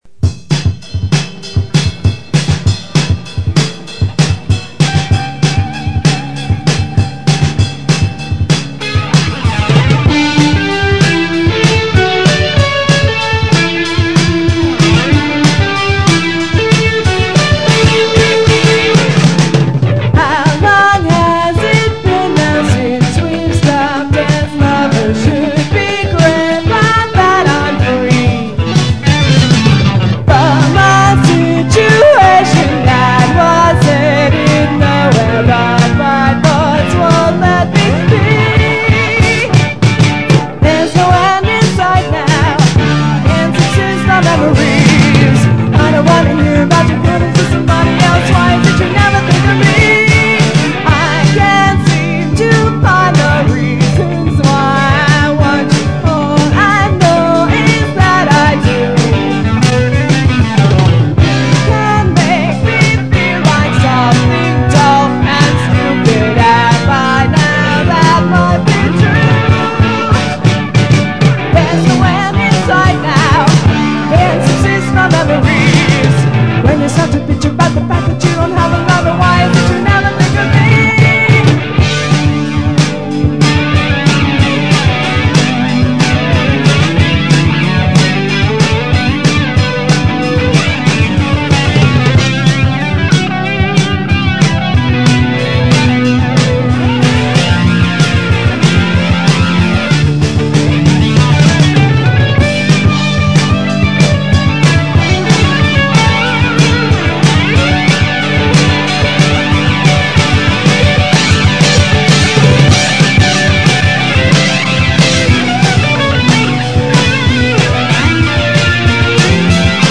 pop/rock/new wave band